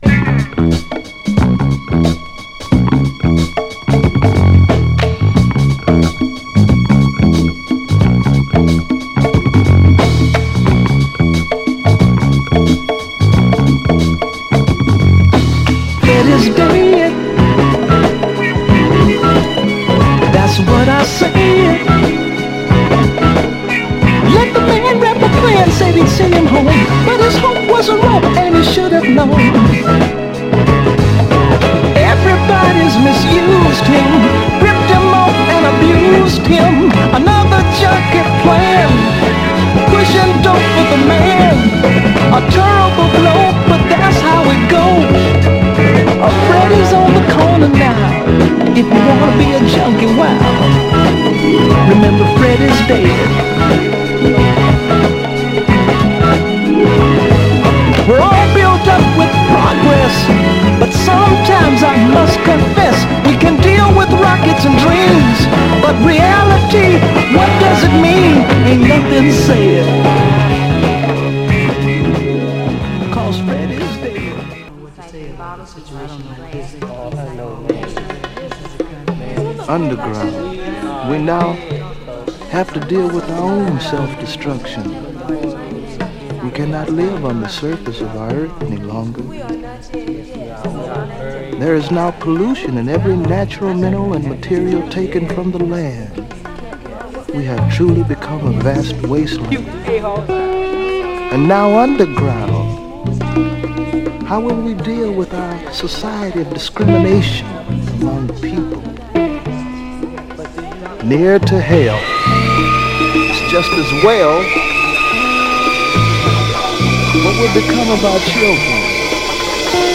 スリリングなずっしりファンクの人気曲です！
※試聴音源は実際にお送りする商品から録音したものです※